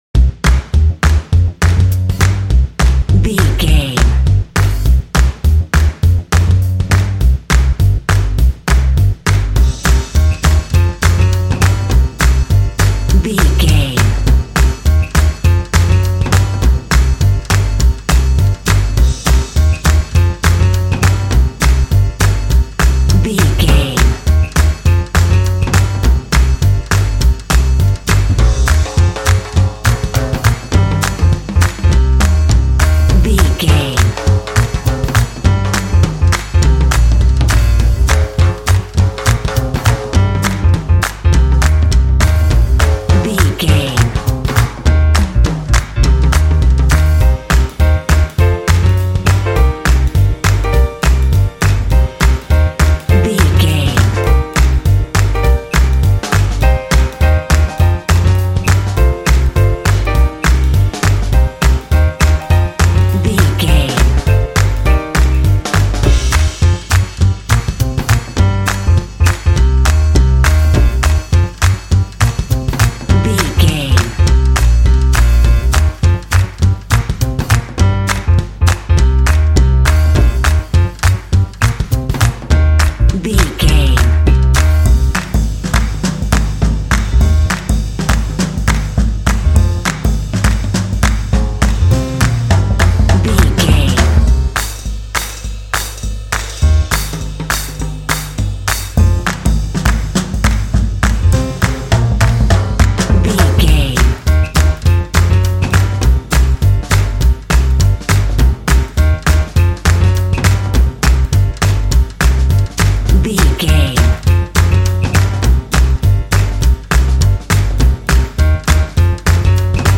Uplifting
Aeolian/Minor
driving
energetic
bouncy
joyful
cheerful/happy
double bass
percussion
drums
piano
big band